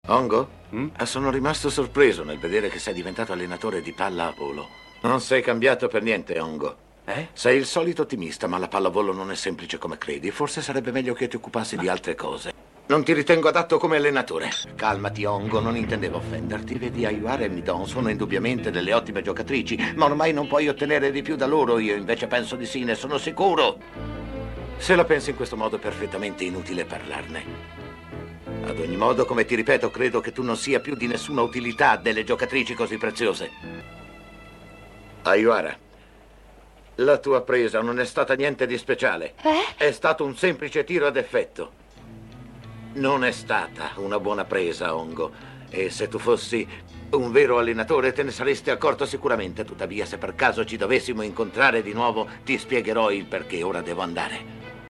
nel cartone animato "Quella magnifica dozzina"